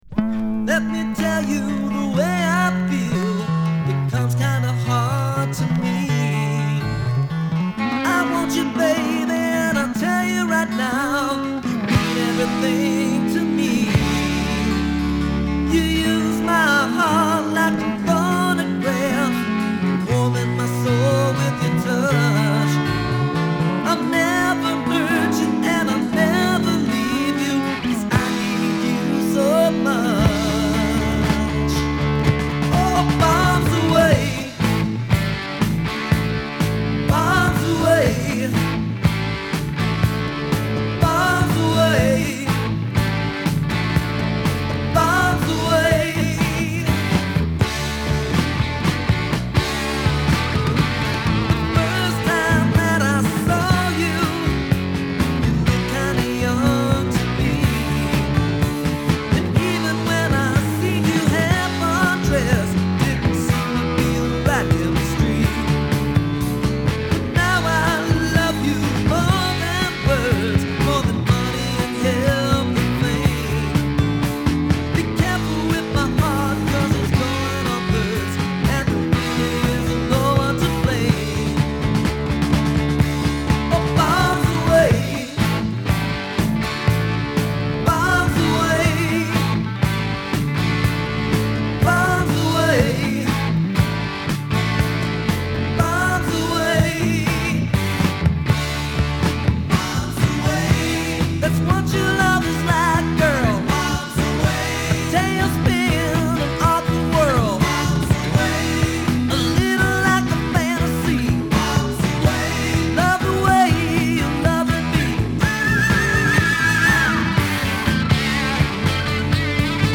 広がりを感じさせるハード・ポップロック。時折繊細さを見せるところがヨシ。